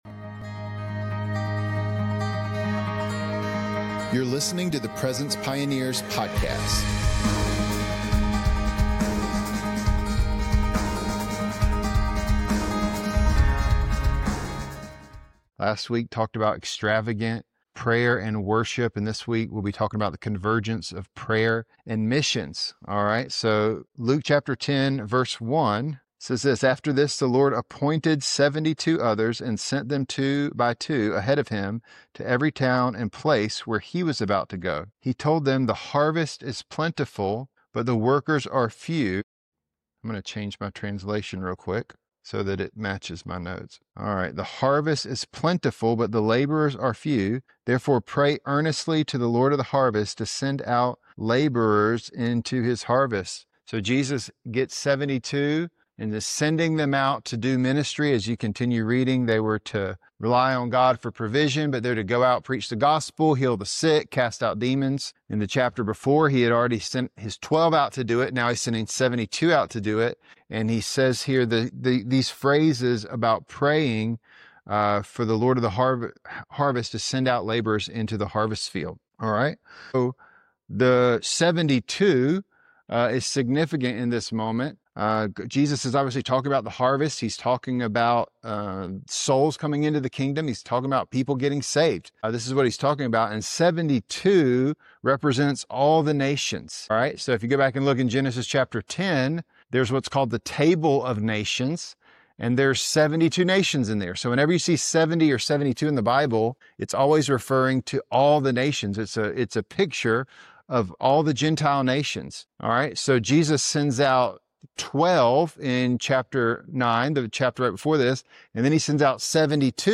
Helping worshipers, intercessors, and leaders experience and host the presence of God - because God's presence changes everything. Featuring interviews and Bible teachings from leaders in the worship & prayer movement on topics such as prophetic worship, intercessory prayer, global missions, unity in the Church, revival, and the tabernacle of David.